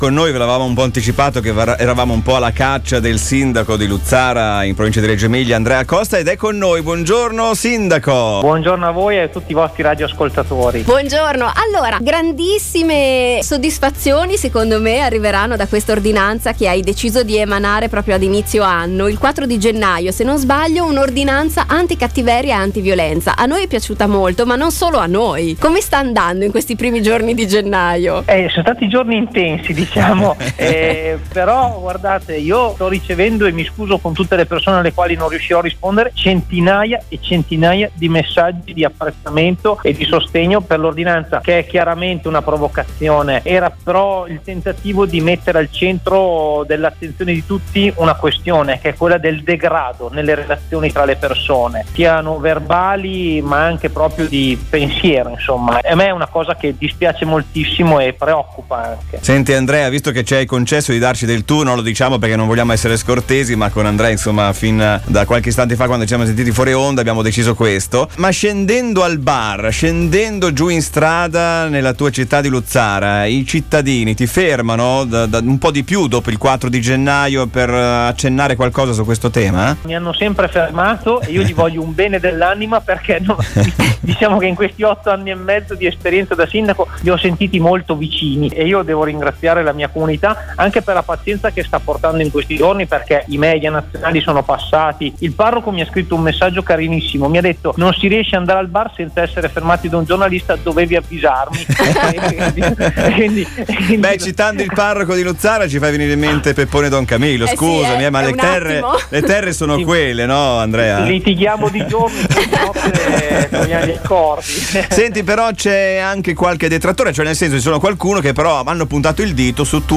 in diretta a Passepartout: